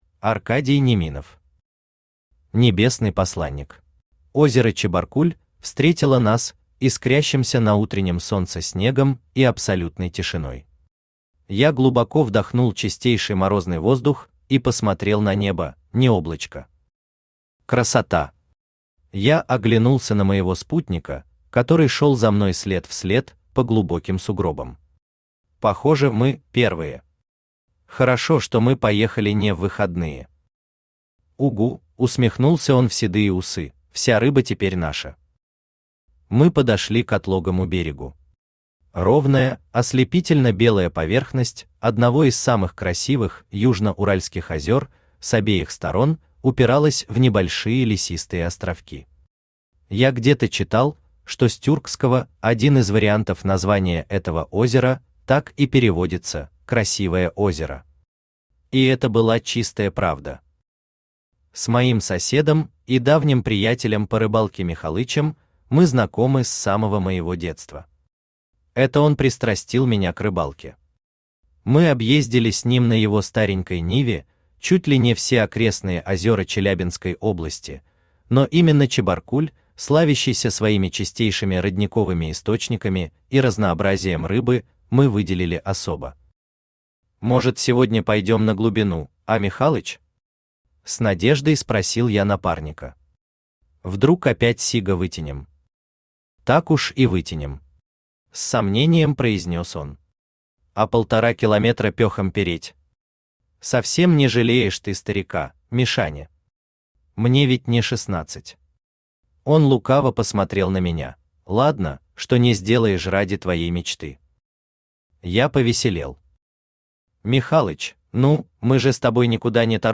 Аудиокнига Небесный посланник | Библиотека аудиокниг
Aудиокнига Небесный посланник Автор Аркадий Неминов Читает аудиокнигу Авточтец ЛитРес.